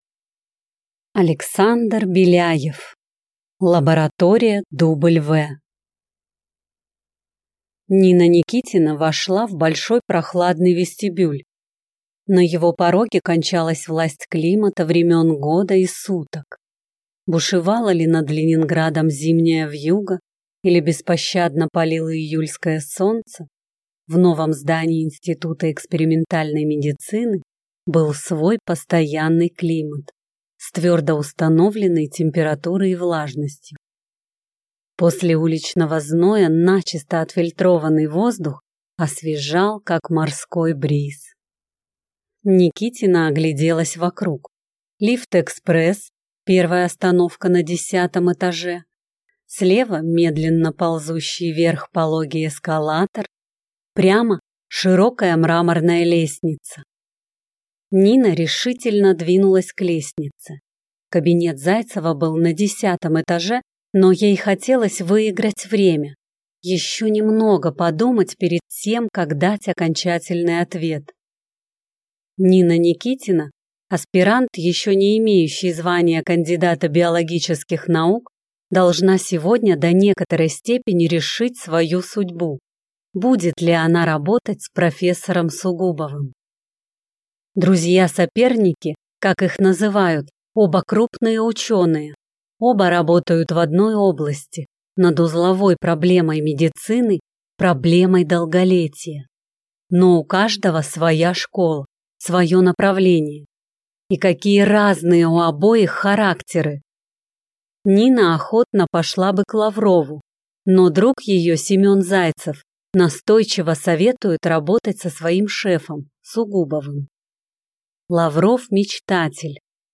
Аудиокнига Лаборатория Дубльвэ | Библиотека аудиокниг